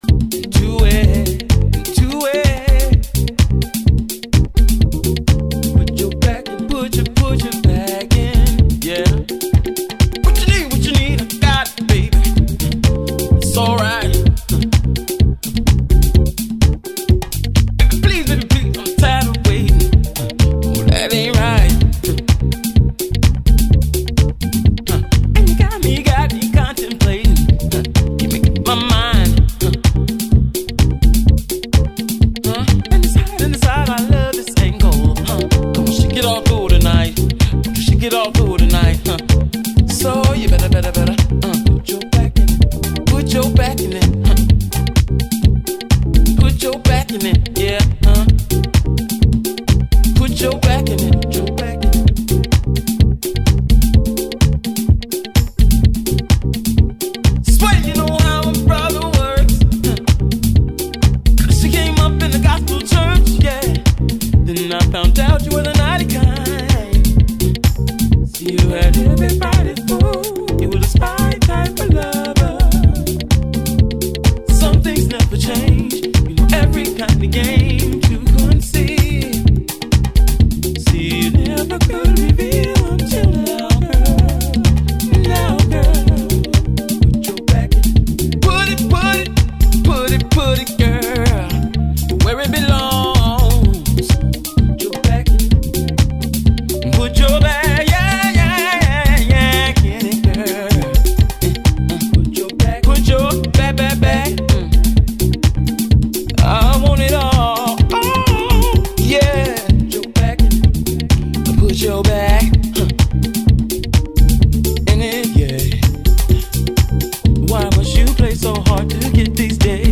Deep house fans must not miss it!